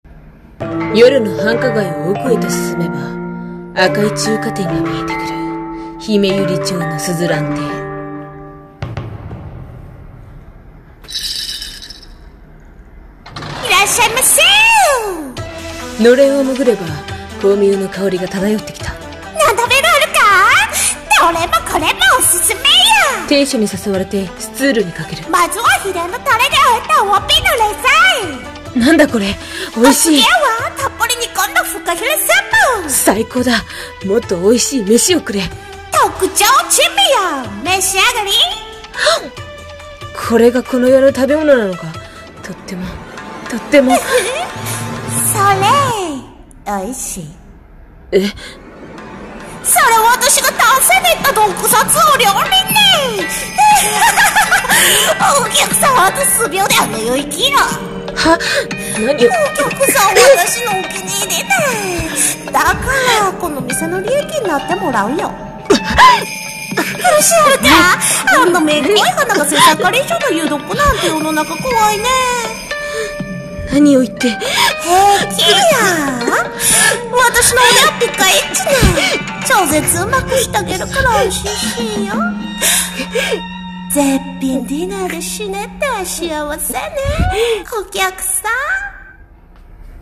【声劇】珍味の鈴蘭中華店